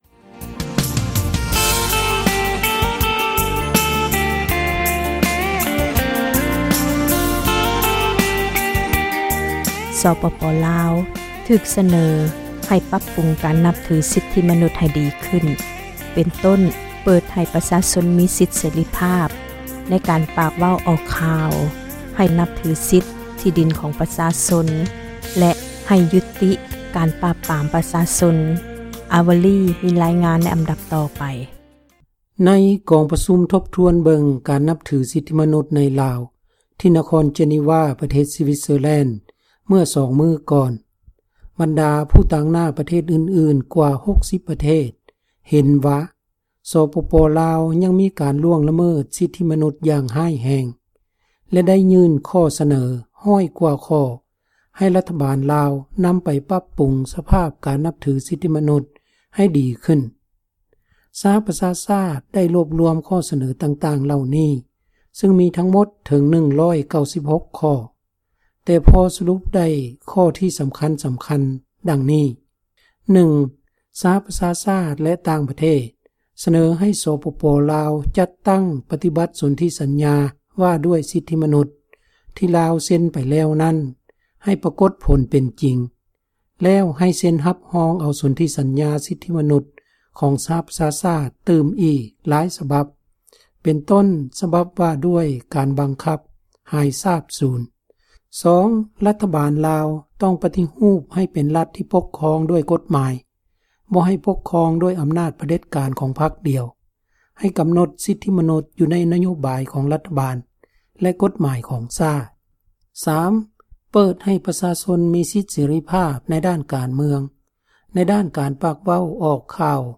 ຣາຍງານ ຈາກ ນະຄອນ ເຈນີວວາ ປະເທດ ສວິຊແລັນ.